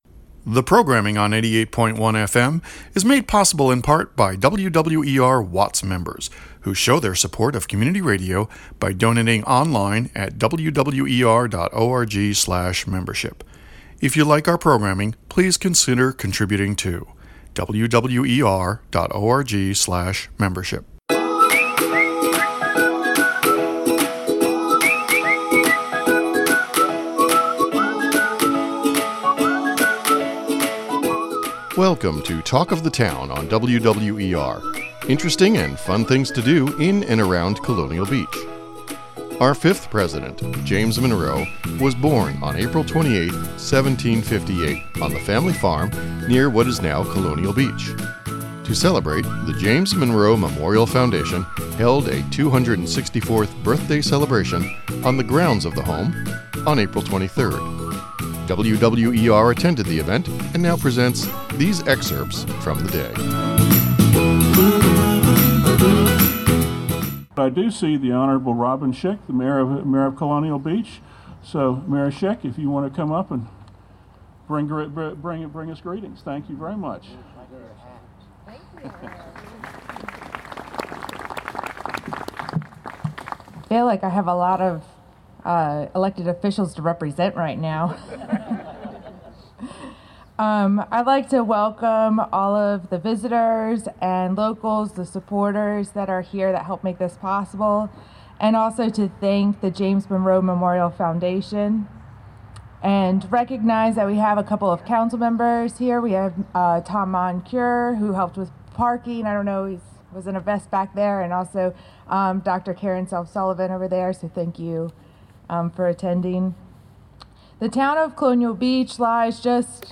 WWER attended the event and now presents these excerpts from the day…